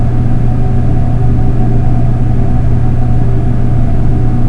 ventilation1.wav